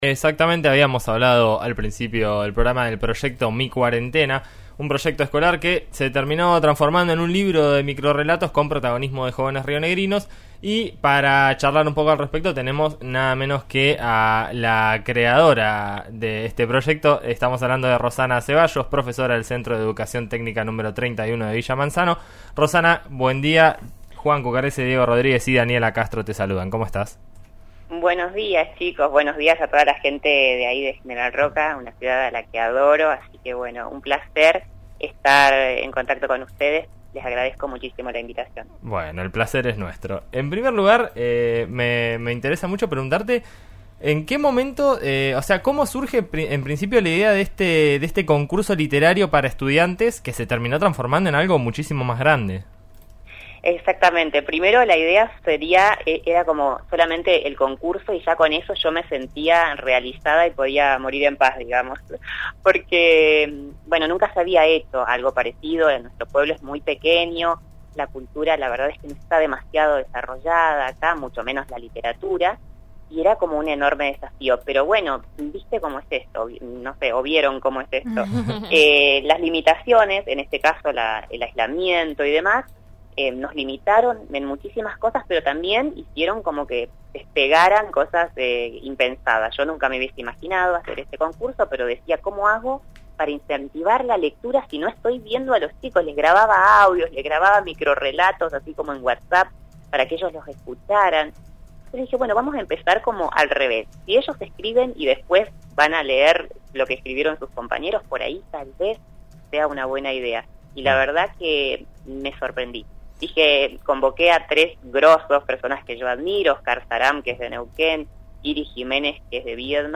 Así lo comentó en diálogo con “En Eso Estamos”, por Río Negro Radio.